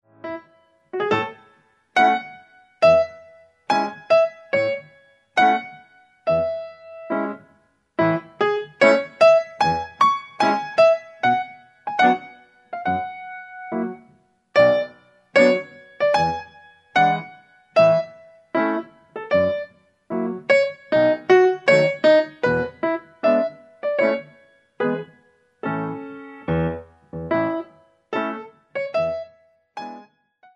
All piano CD for Pre- Ballet classes.